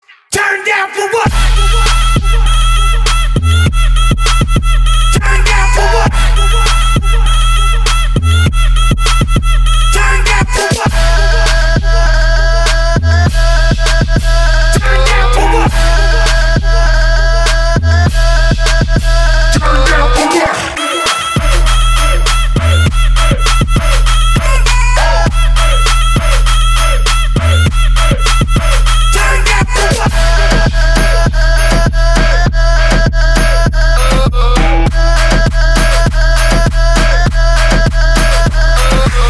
Kategorie Marimba Remix